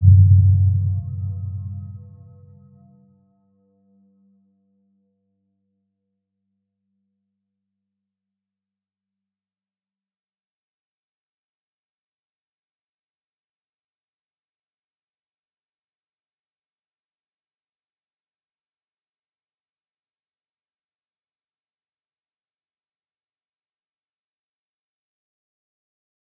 Round-Bell-G2-mf.wav